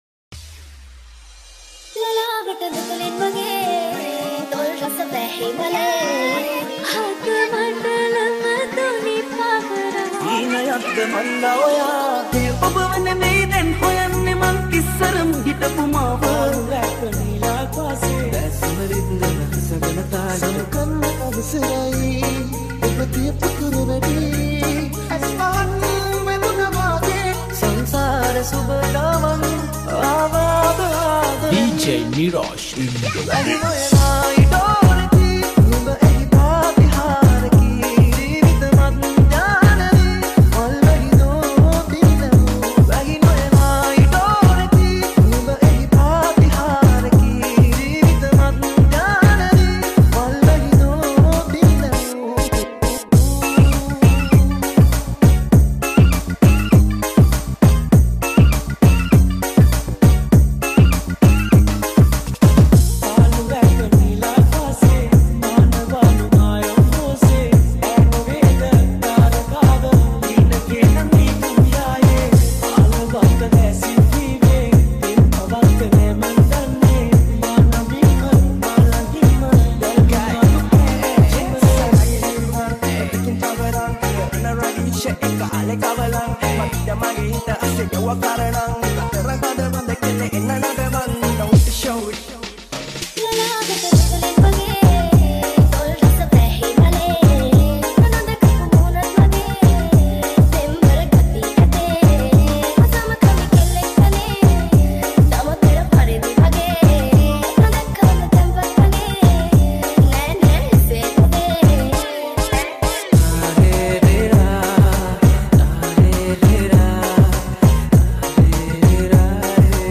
Sinhala Remix